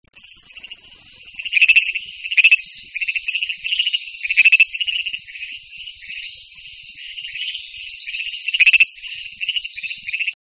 chant: audible à quelques mètres, sur la végétation au-dessus des mares temporaires, marais et milieux anthropisés:
chant leucophyllata.mp3